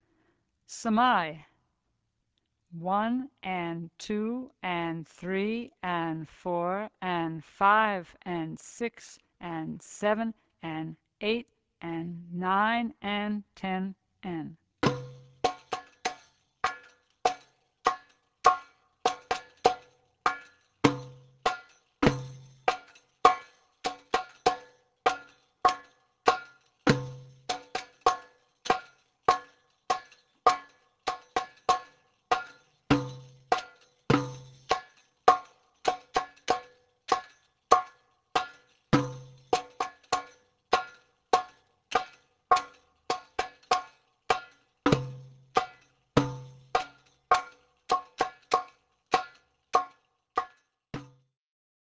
doumbec and tambourine
Sama'i